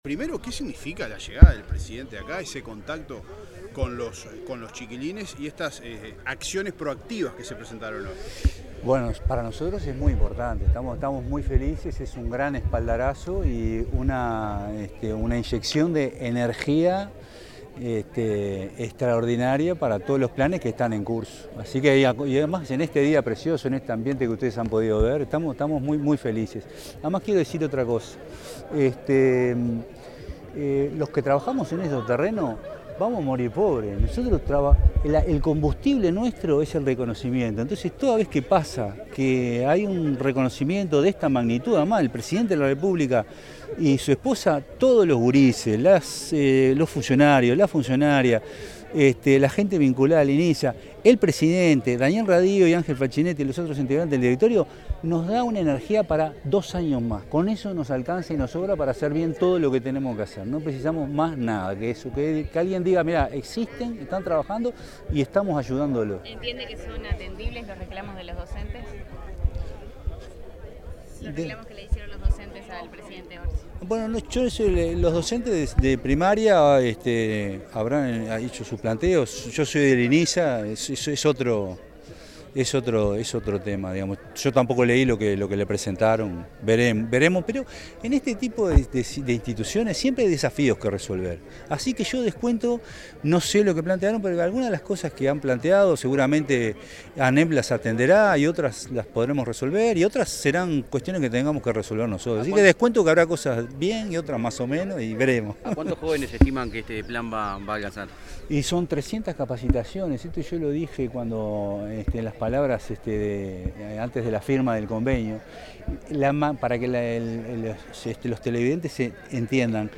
Declaraciones del presidente de Inisa, Jaime Saavedra
Durante la firma de un convenio entre los institutos nacionales de Inclusión Social Adolescente y de Empleo y Formación Profesional, el presidente del